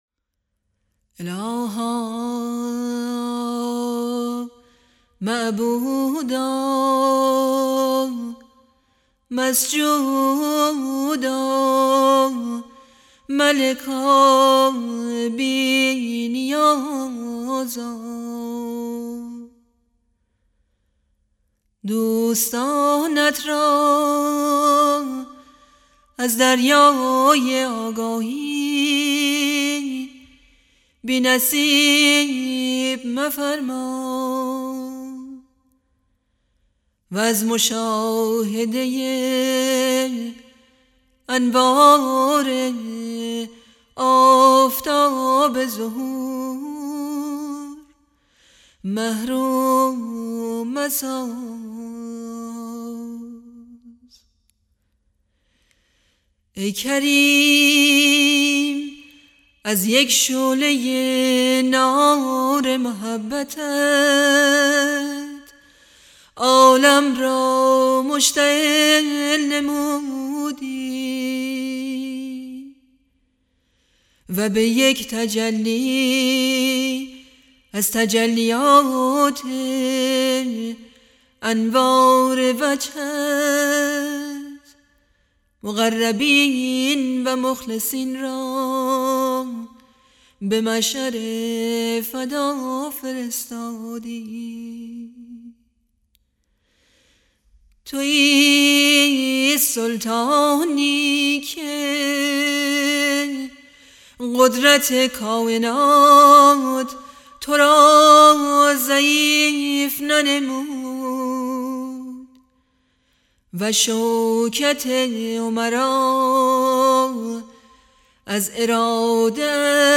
مناجات های صوتی فارسی